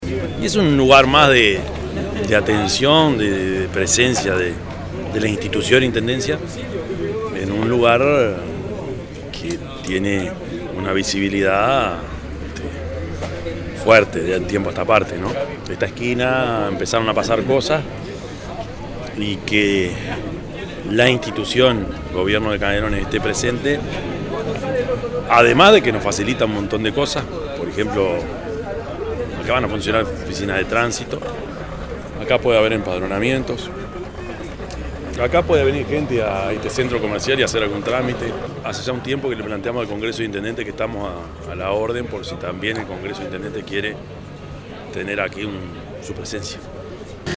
yamandu_orsi_intendente_0.mp3